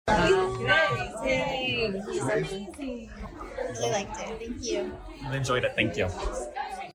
KMAN was there to get their feedback.